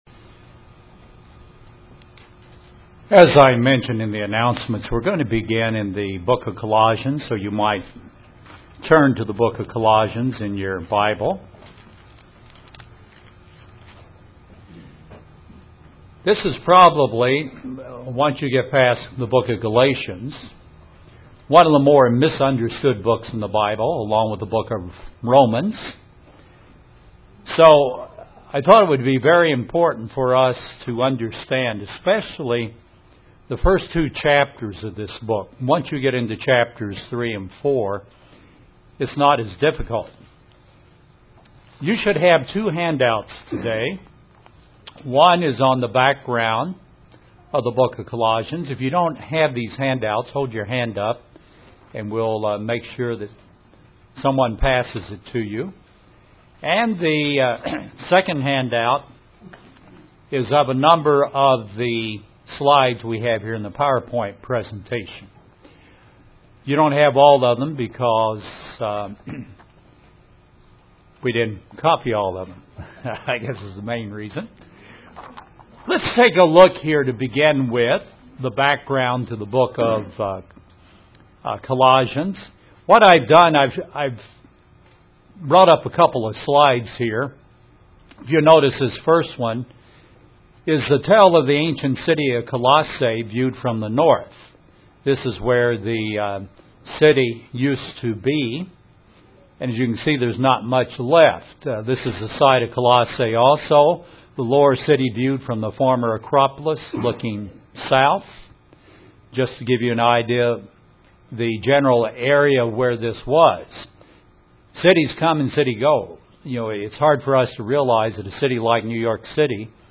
A Bible study on the book of Colossians.